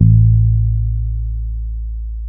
-MM DUB  A 2.wav